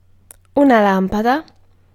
Ääntäminen
IPA : /laɪt/